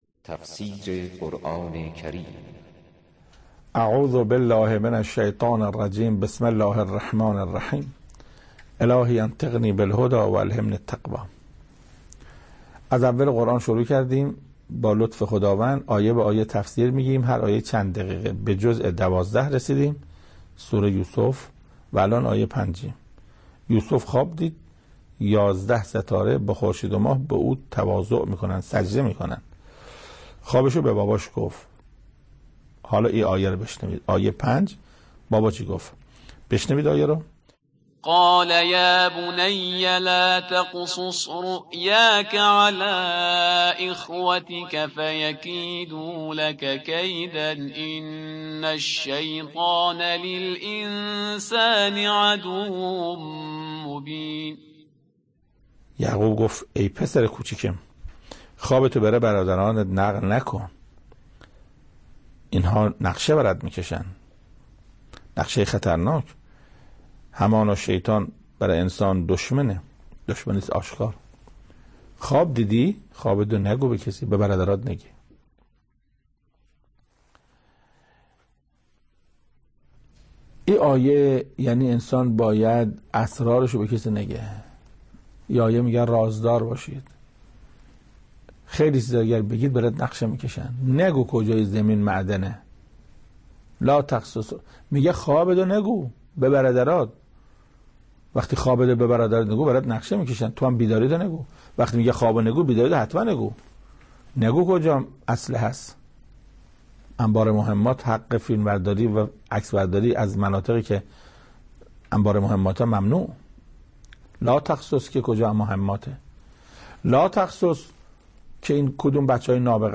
تفسیر صوتی قرآن کریم، حجت‌الاسلام قرائتی: سوره یوسف آیه 5